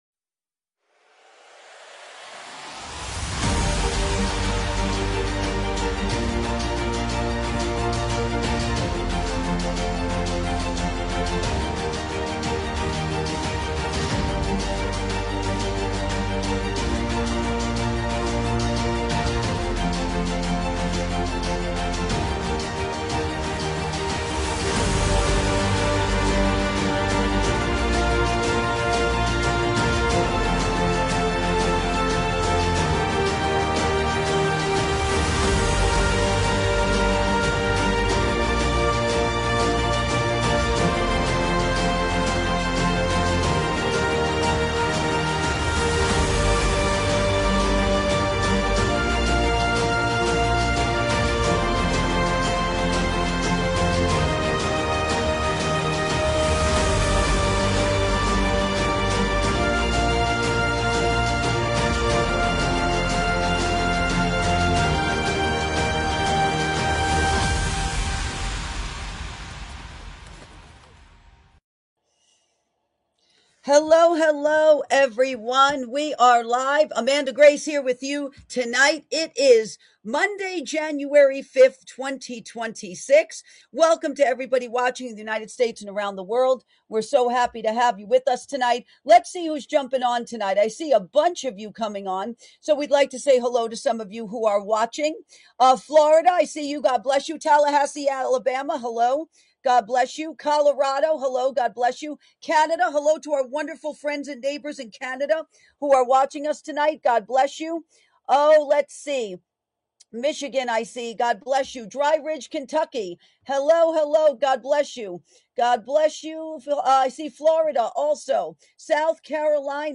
Inspirational Cinematic Music
Talk Show